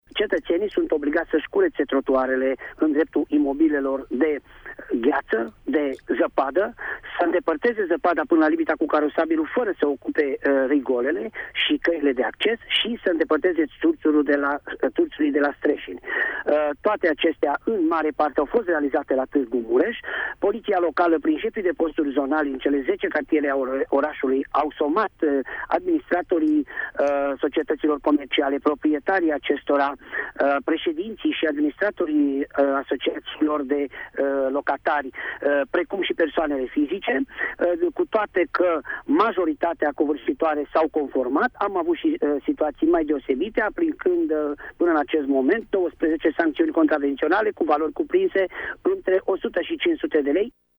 Târgumureșenii sunt obligați să efectueze această operațiune, în baza unei hotărâri a Consiliului Local din anul 2008, a precizat șeful poliței Locale Tîrgu- Mureș, Valentin Bretfelean, în emisiunea Sens Unic de la RTM: